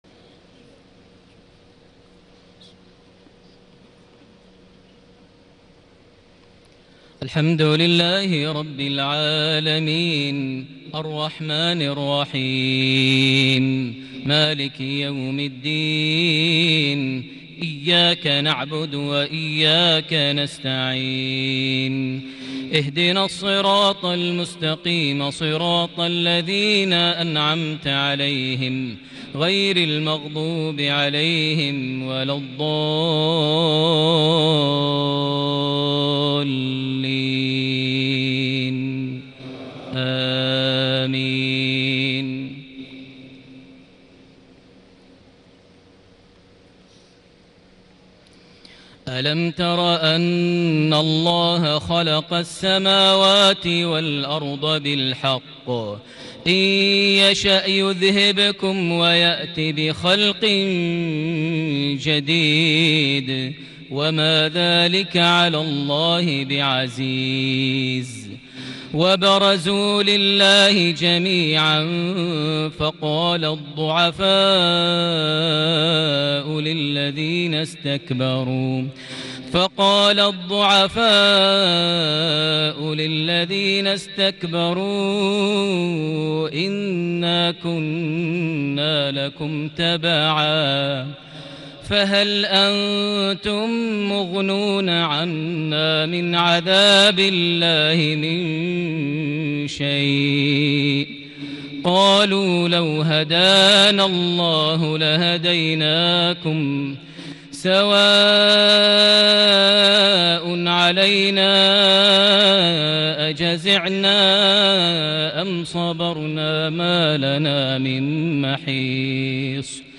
صلاة العشاء ٩ محرم ١٤٤٠هـ سورة إبراهيم ( ١٩-٢٧) > 1440 هـ > الفروض - تلاوات ماهر المعيقلي